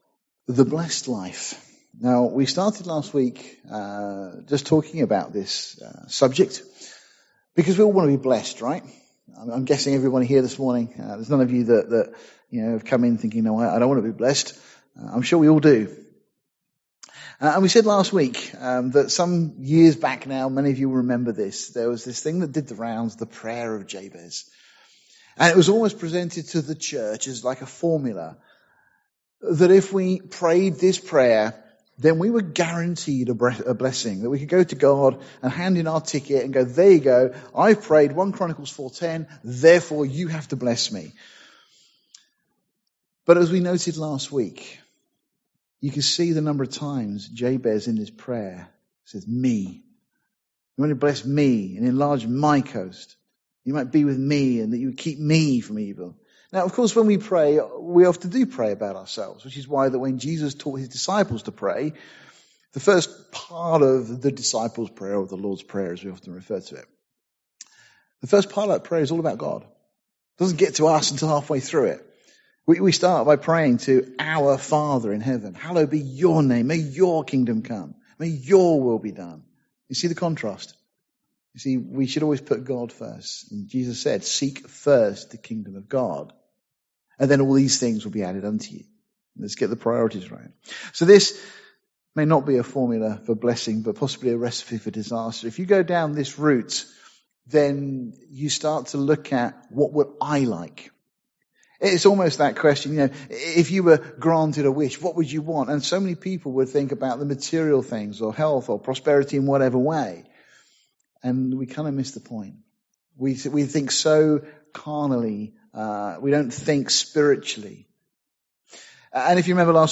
Series: A Blesssed Life , Sunday morning studies Tagged with topical studies , verse by verse